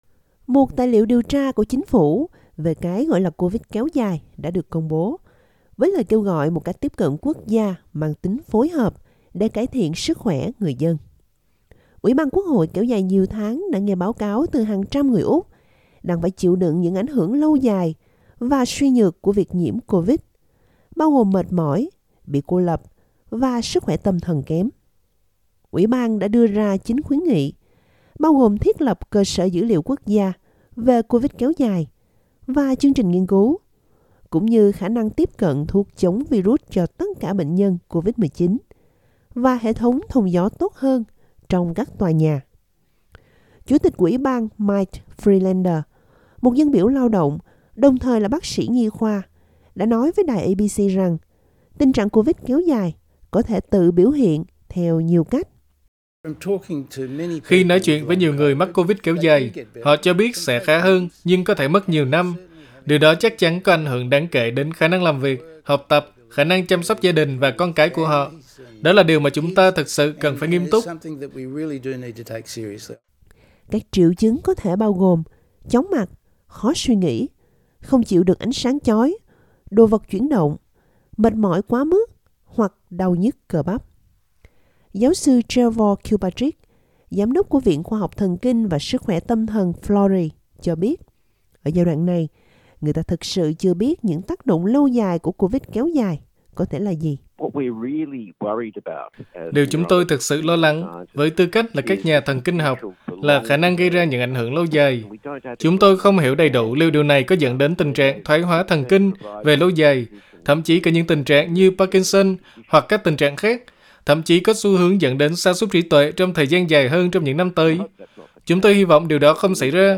vietnamese-covid-report-264.mp3